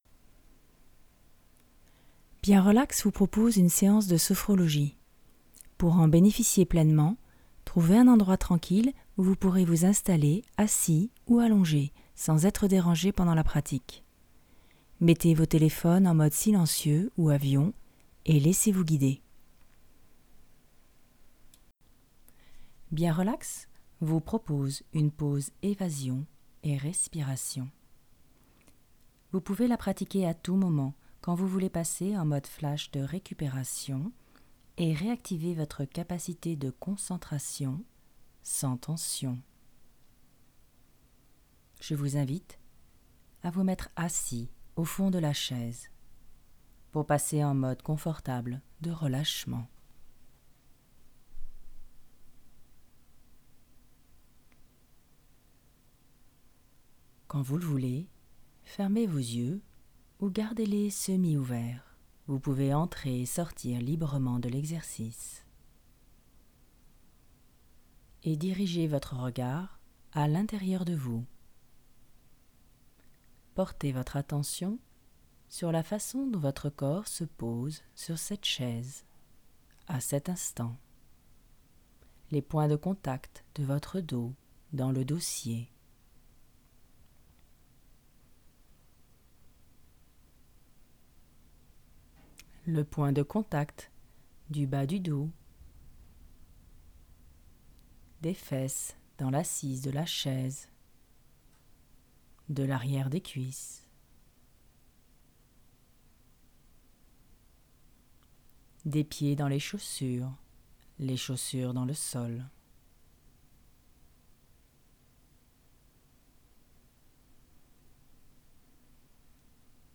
Genre : Sophrologie